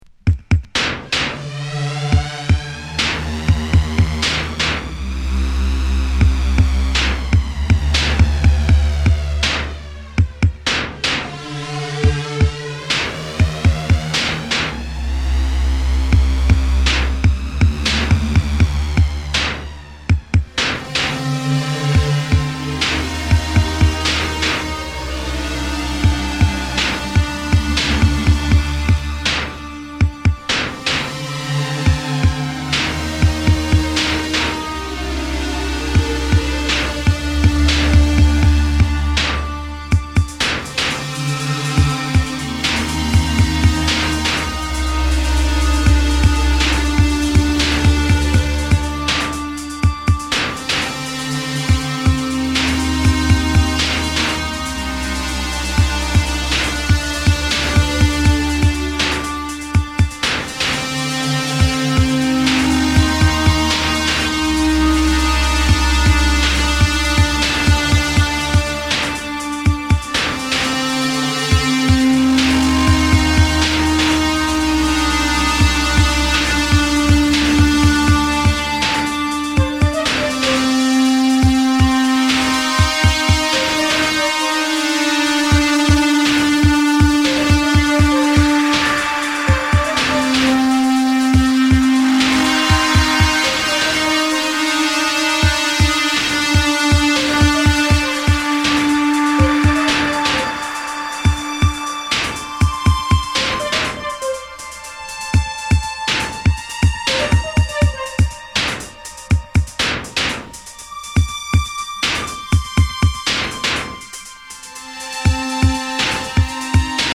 尖ったインダストリアル・ビート主体ながらエレクトロ、テクノ、ミニマルをもACID感覚も絡めつつ突き進む狂気の全13曲！